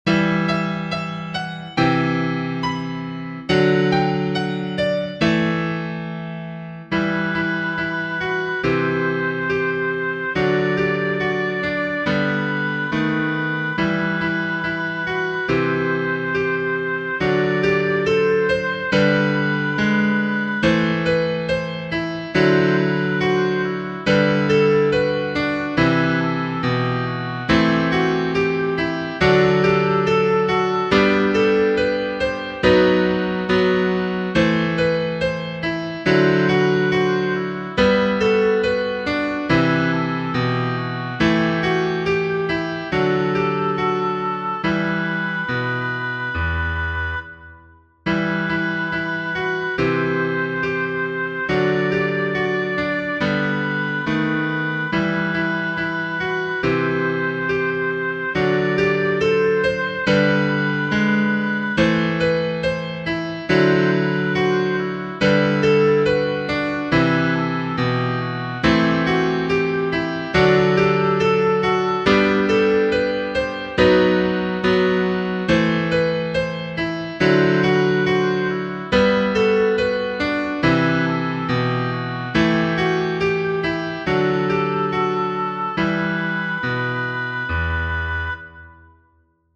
Genere: Religiose
è un canto liturgico cattolico